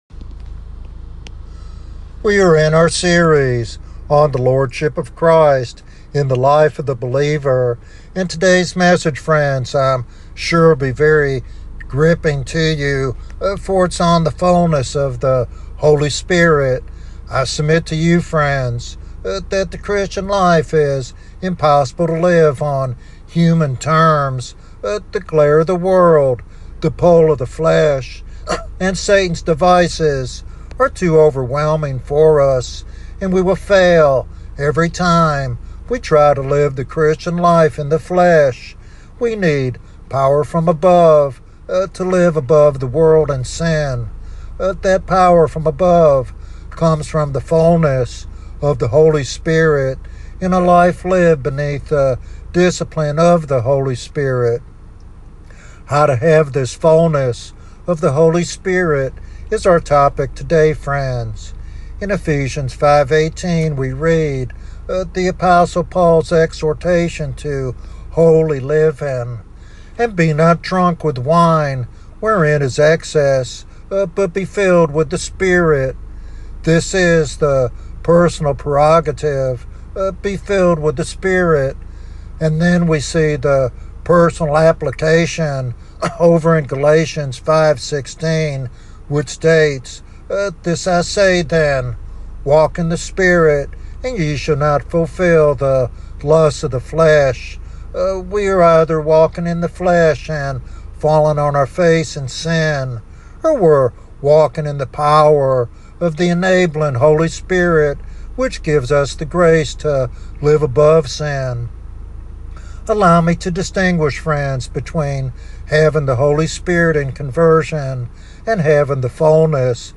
In this teaching sermon